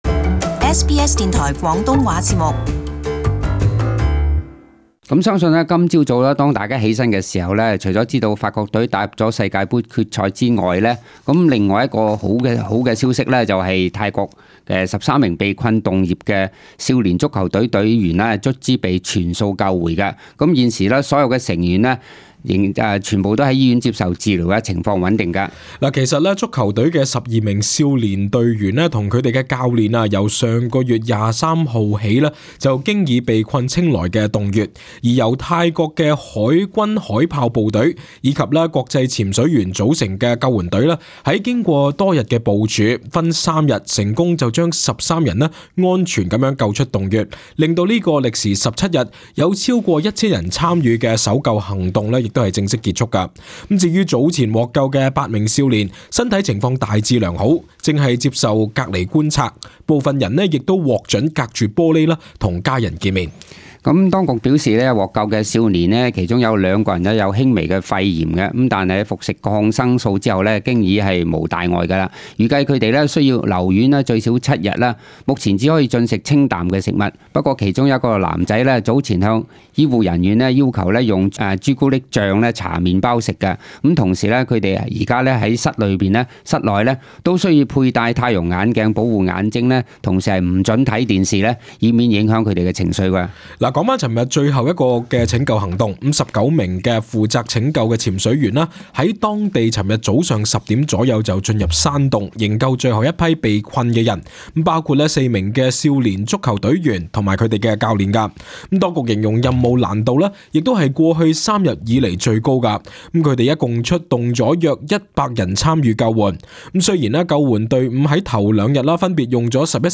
【時事報導】被困洞穴的泰國少年足球隊12名成員及其教練已被救出